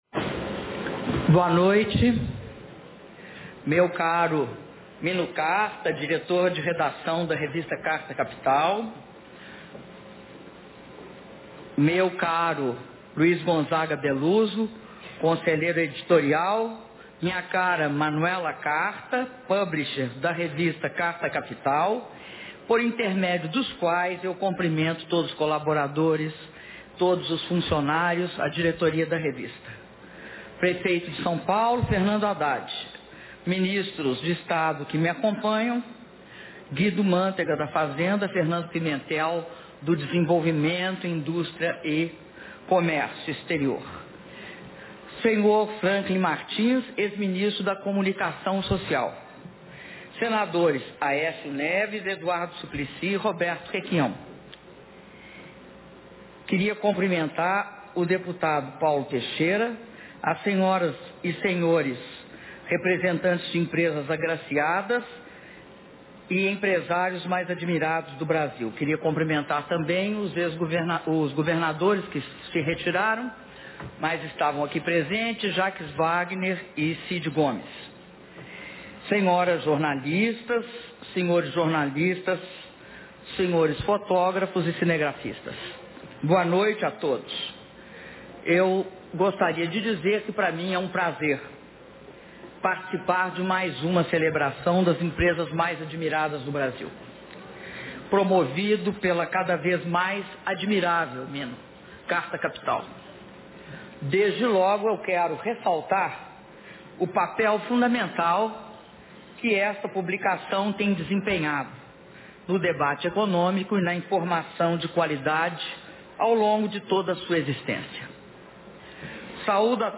Áudio do discurso da Presidenta da República, Dilma Rousseff, na cerimônia de premiação das empresas mais admiradas no Brasil (27min30s)
Discurso da Presidenta Dilma Rousseff durante cerimônia de premiação das empresas mais admiradas no Brasil - São Paulo/SP